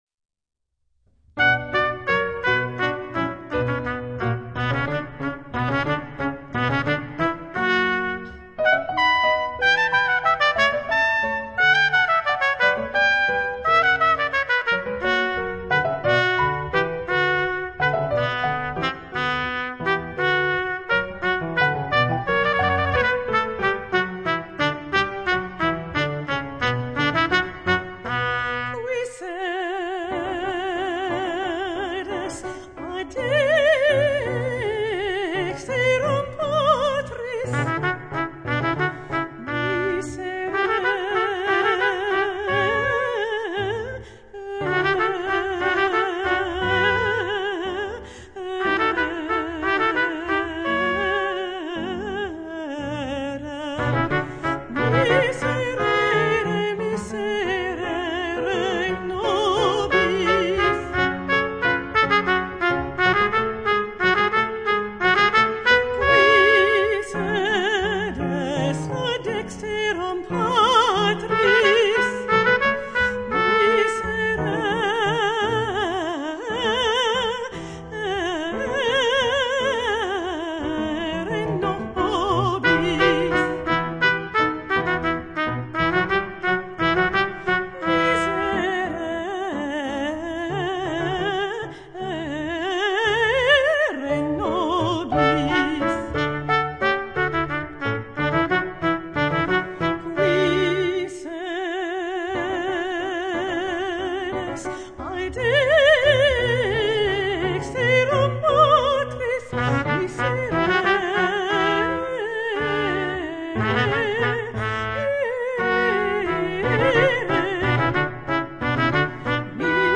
Voicing: High Voice Collection